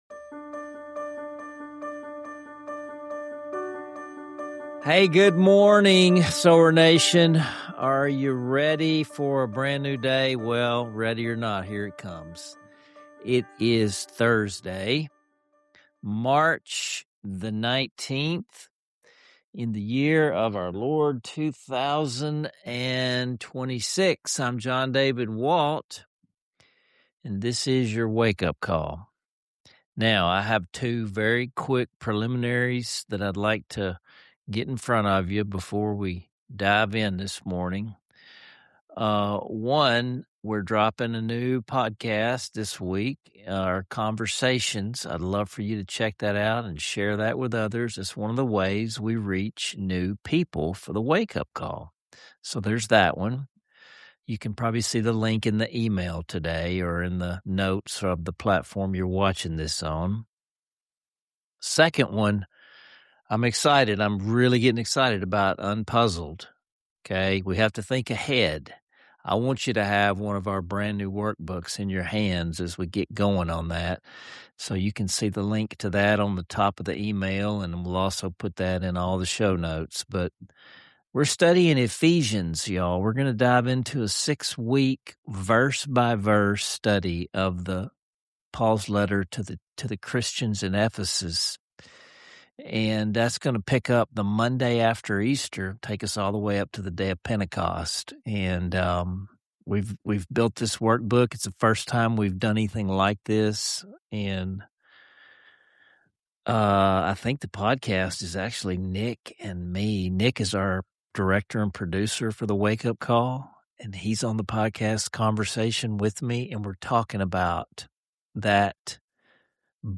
A soul-lifting rendition of the classic hymn “Love Lifted Me,” bringing the message home with hope and song.